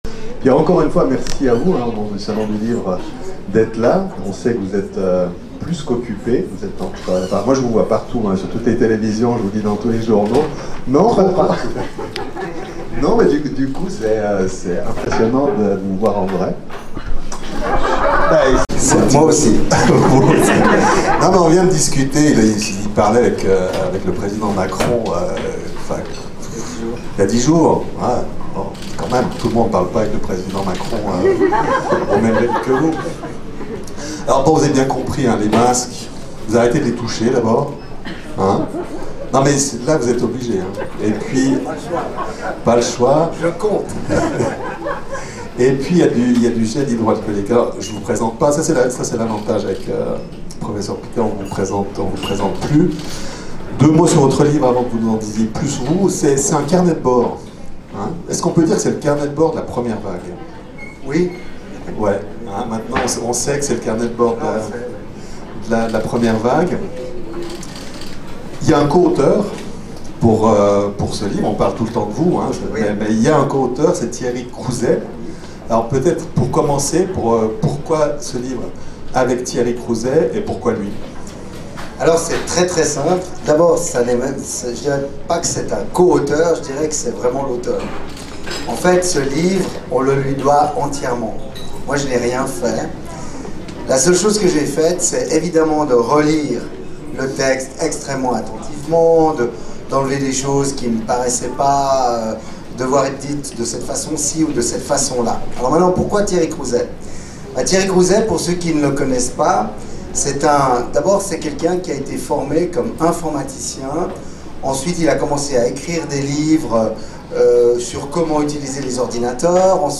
Payot Rive Gauche (GE) Didier Pittet